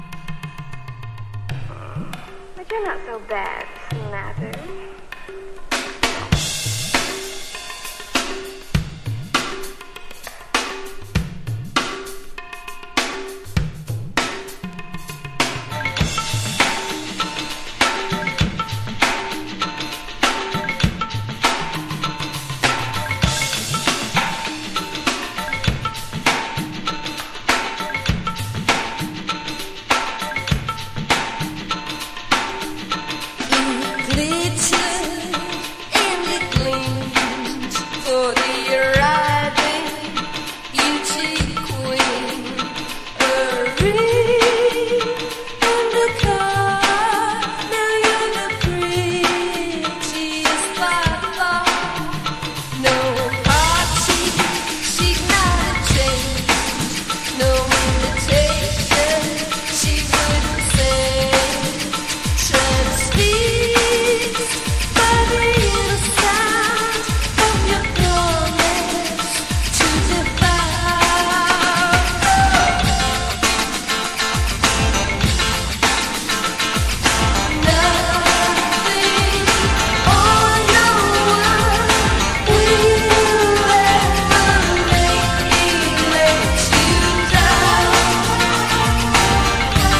エキゾなメロディーにテクノ～ヒップホップ要素を感じる彼らの新境地的な楽曲!!
# NEW WAVE# POST PUNK# 80’s ROCK / POPS